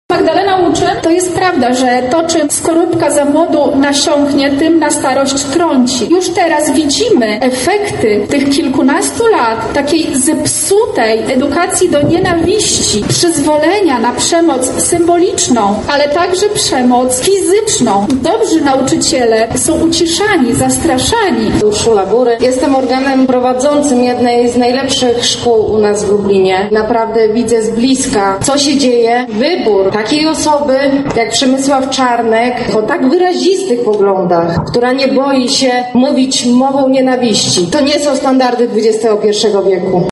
Na miejscu był nasz reporter:
protest pod ratuszem